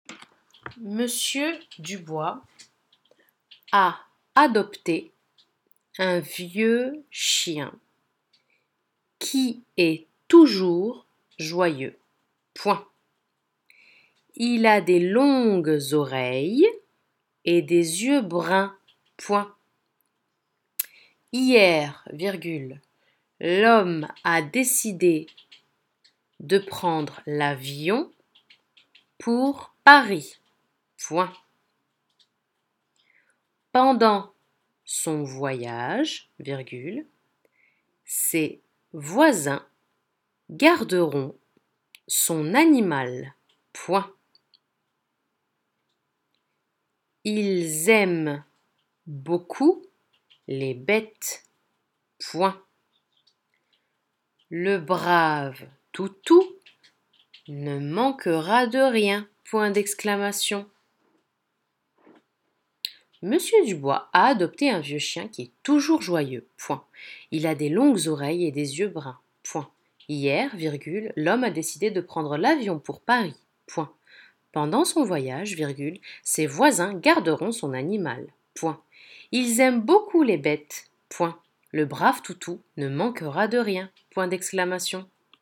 JEBxbDPuUni_dictée-2.m4a